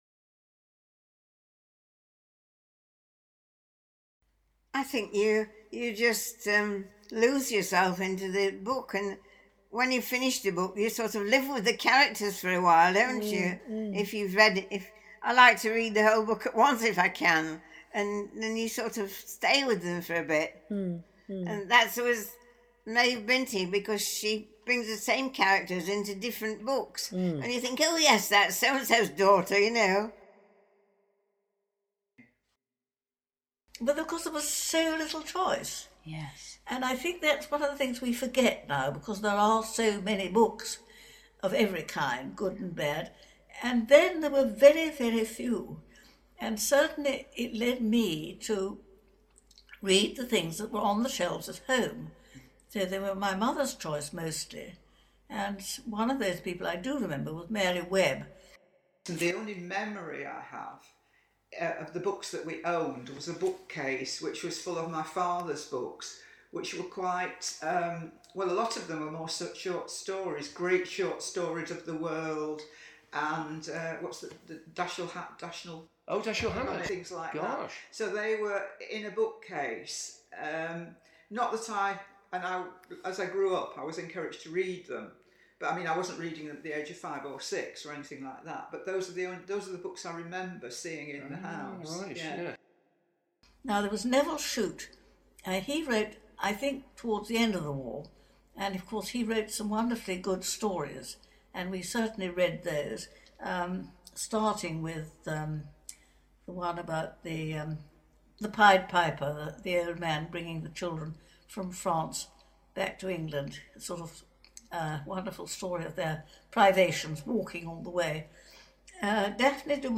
Final-Reading-Sheffield.mp3